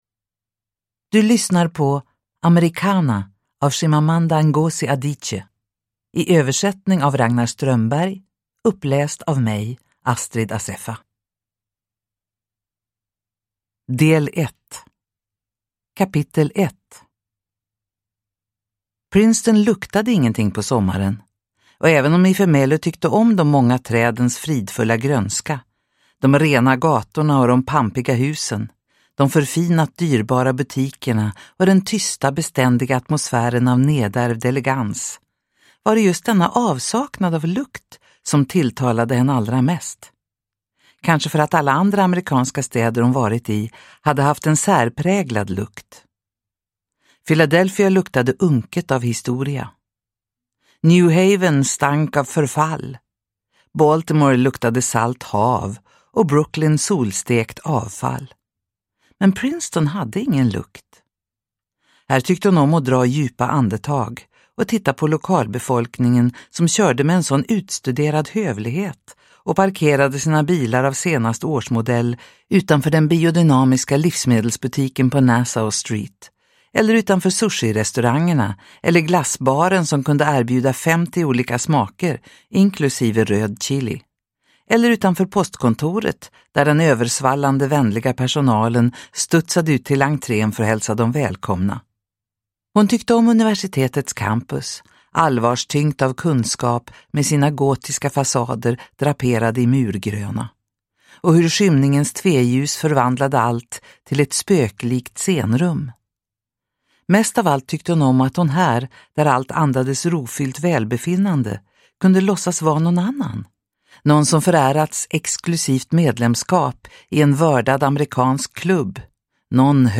Americanah – Ljudbok – Laddas ner